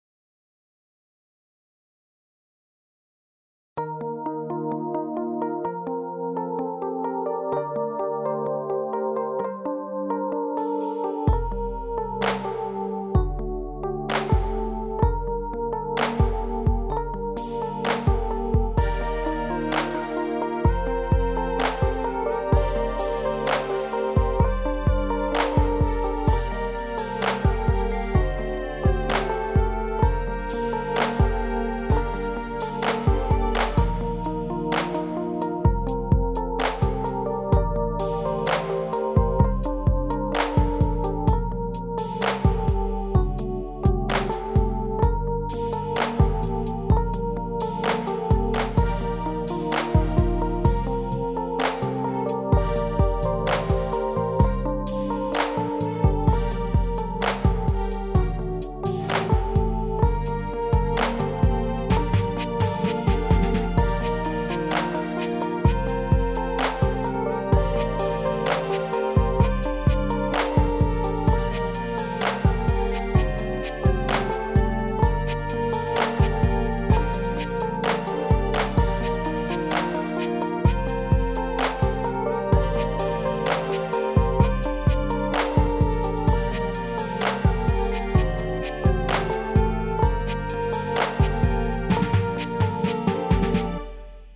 아, 이 파일은 가믹싱 상태로 데모는 아닙니다.